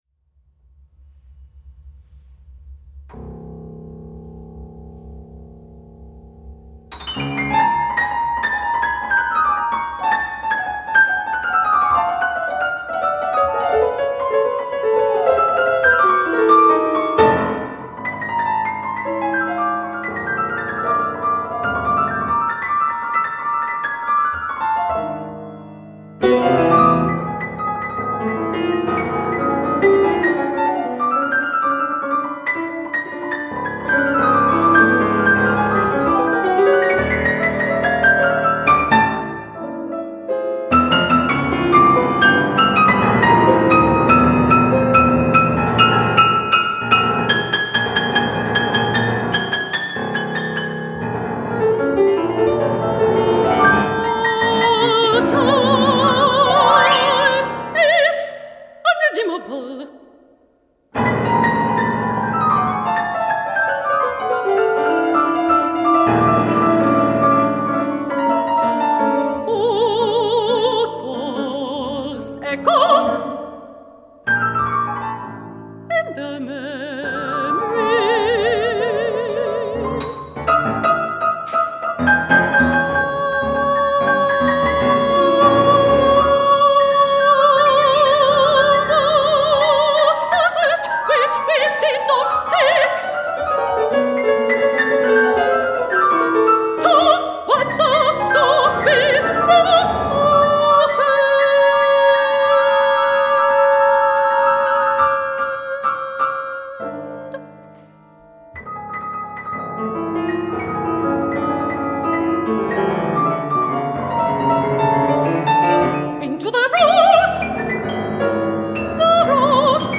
for female voice and piano